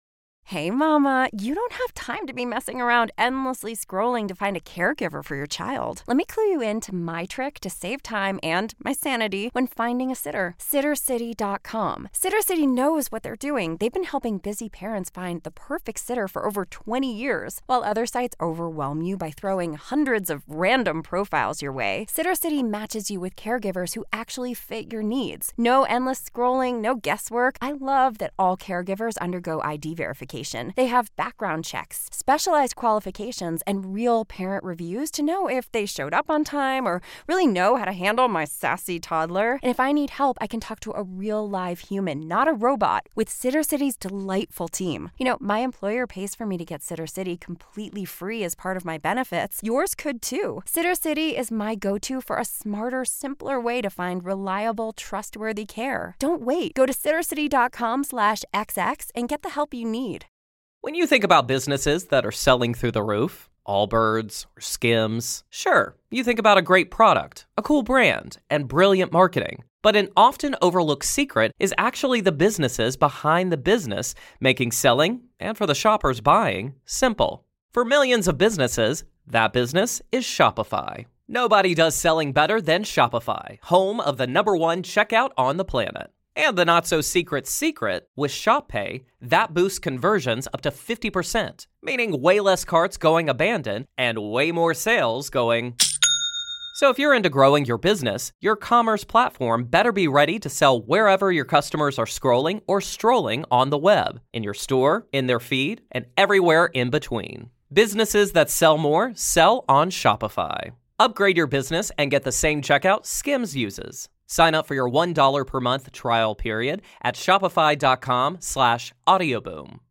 The podcast is hosted and written by Diane Chorley.